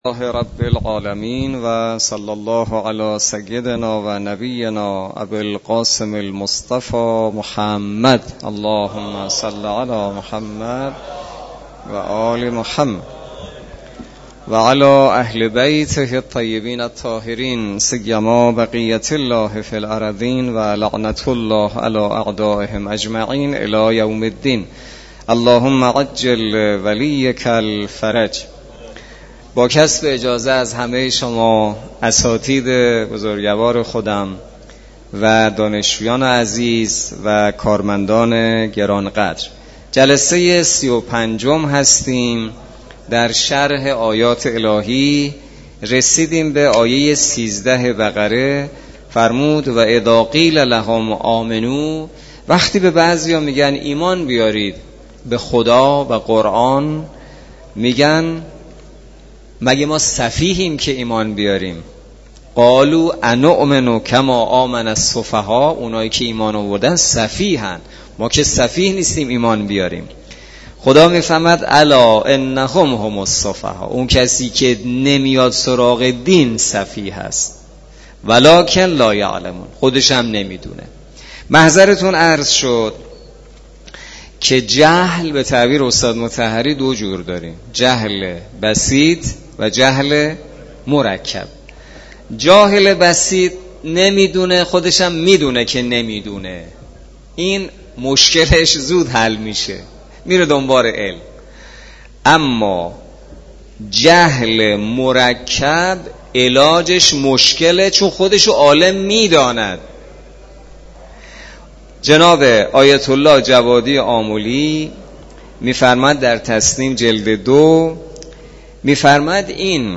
برگزاری هجدهمین جلسه تفسیر سوره مبارکه بقره توسط امام جمعه کاشان در مسجد دانشگاه.
هجدهمین جلسه تفسیر سوره مبارکه بقره توسط حجت‌الاسلام والمسلمین حسینی نماینده محترم ولی فقیه و امام جمعه کاشان در مسجد دانشگاه کاشان برگزار گردید.